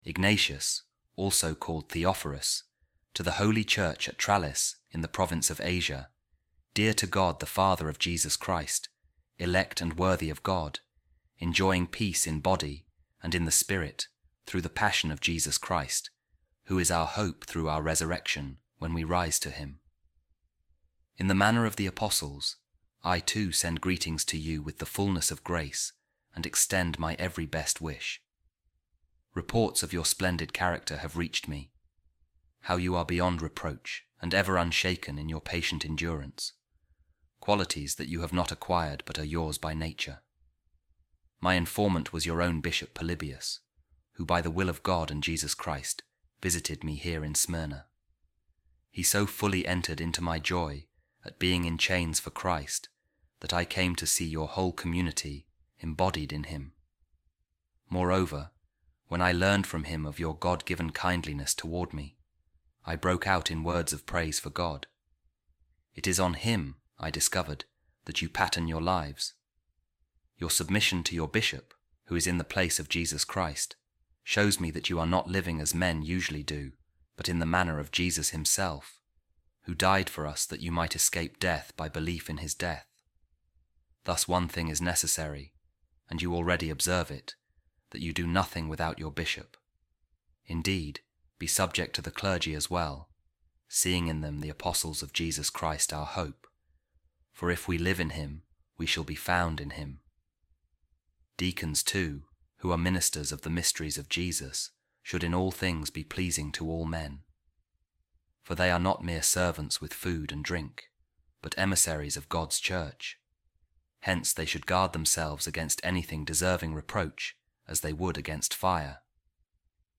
A Reading From The Letter Of Saint Ignatius Of Antioch To The Trallians | I Wish To Protect You In Good Time Because You Are Dear To My Heart